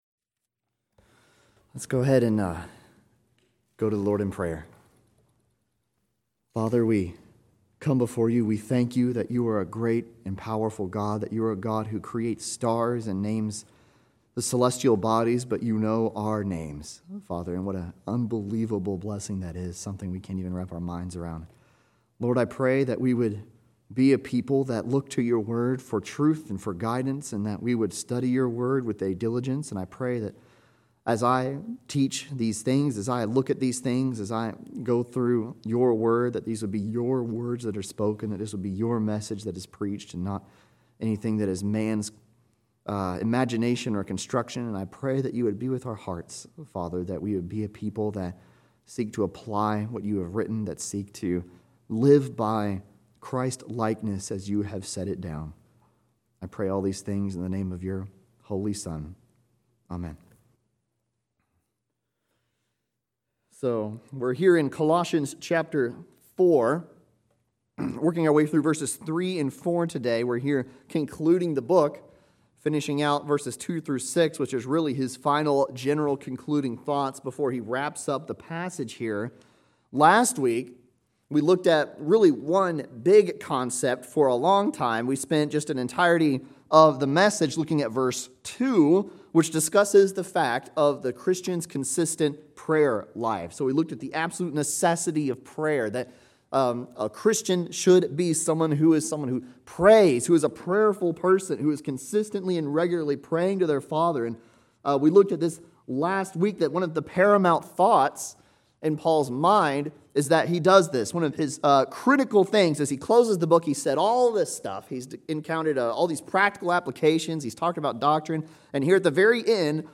Colossians 4:3-4 (CLICK FOR SERMON NOTES)